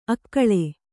♪ akkaḷe